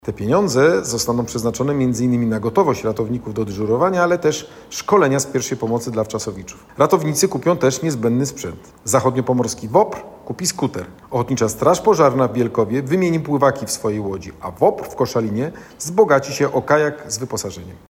Adam Rudawski, Wojewoda Zachodniopomorski: